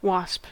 Ääntäminen
Etsitylle sanalle löytyi useampi kirjoitusasu: wasp WASP Ääntäminen US UK : IPA : /ˈwɒsp/ US : IPA : /ˈwɑsp/ Haettu sana löytyi näillä lähdekielillä: englanti Käännös Substantiivit 1.